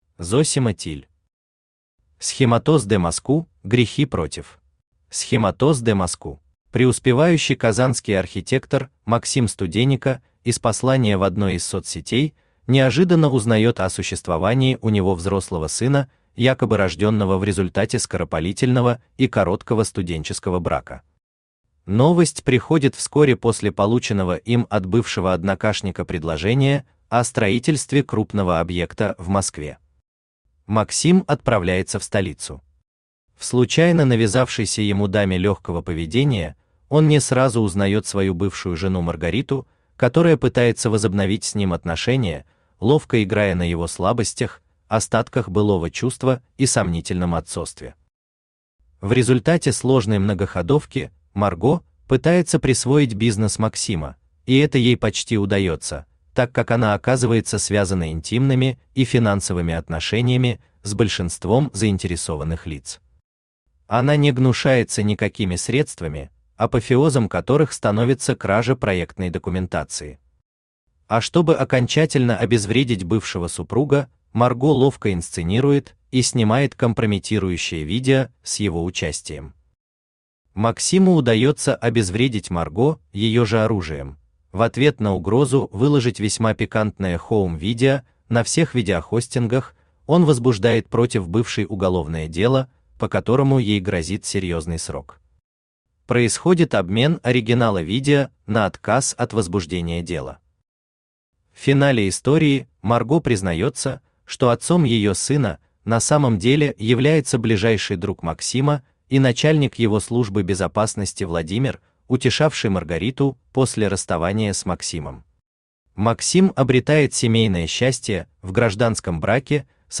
Аудиокнига Схемотоз де Моску́ / Грехи против | Библиотека аудиокниг
Aудиокнига Схемотоз де Моску́ / Грехи против Автор Зосима Тилль Читает аудиокнигу Авточтец ЛитРес.